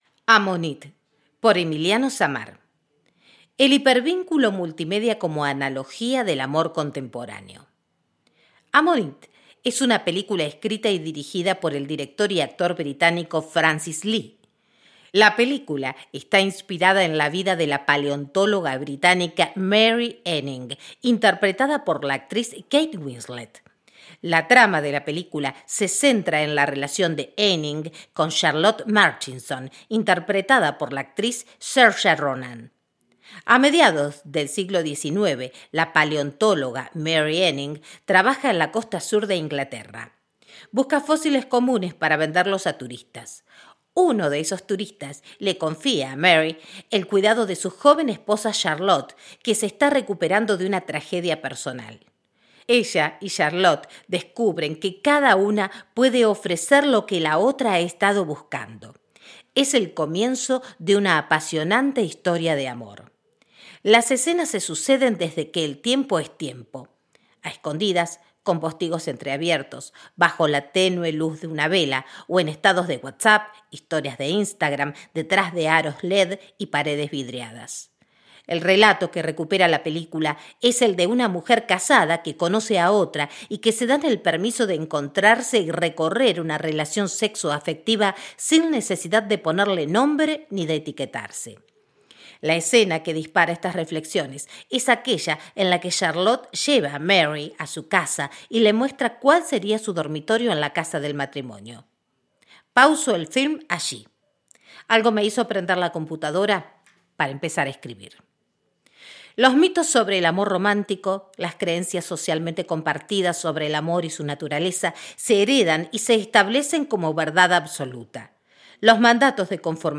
Escucha este artículo en la voz de